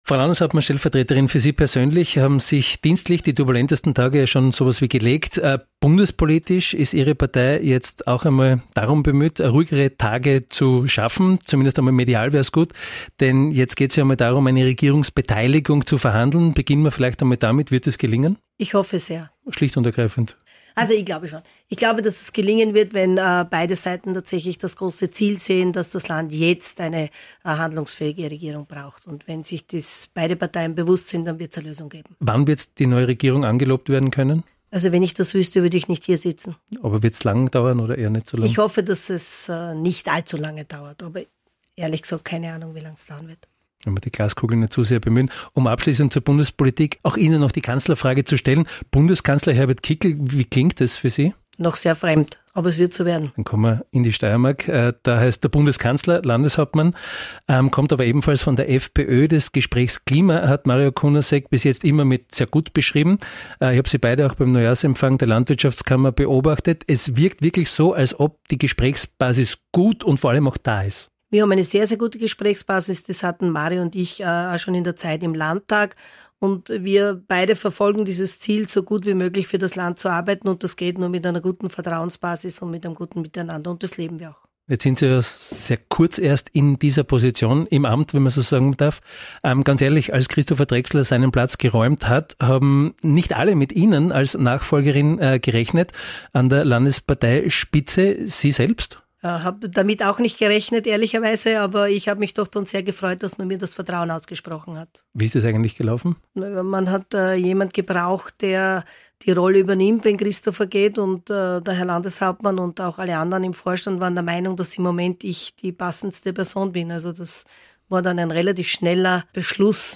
Antritts-Interview mit Manuela Khom von der ÖVP - 09.01.2025